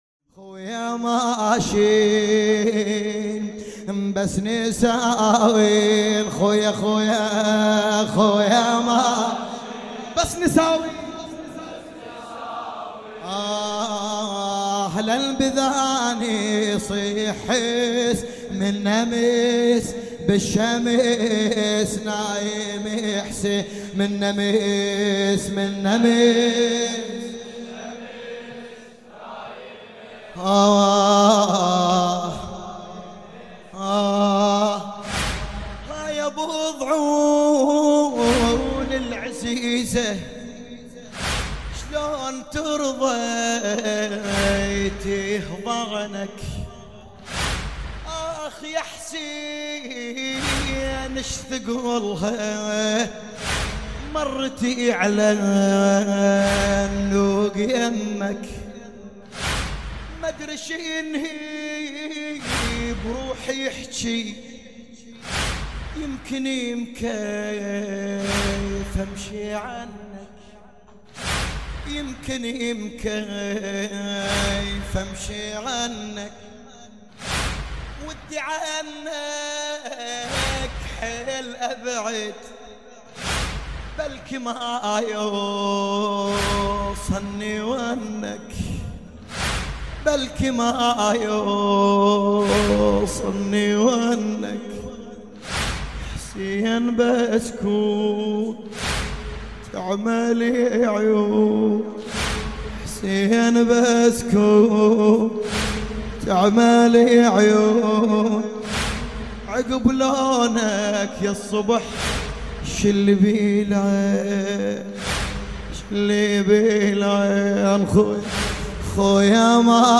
لطميات